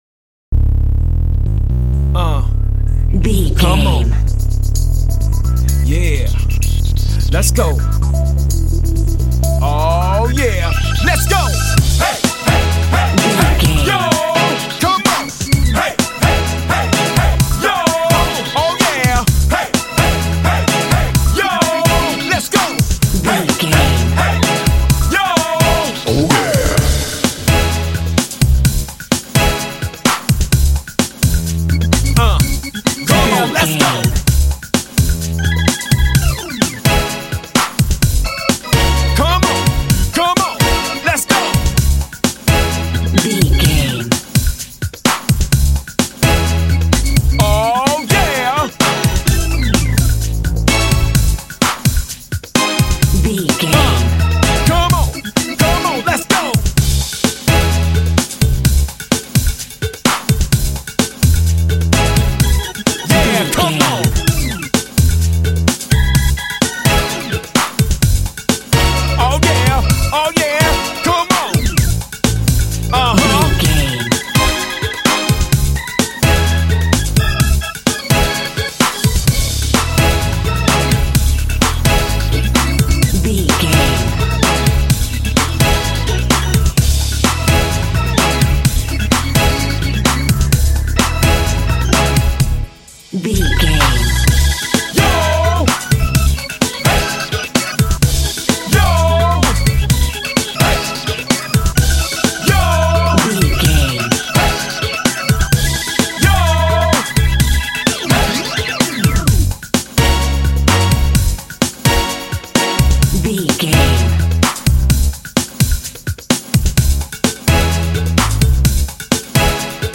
Epic / Action
Aeolian/Minor
C#
funky
bouncy
groovy
synthesiser
drums
electric organ
percussion
strings
vocals
r& b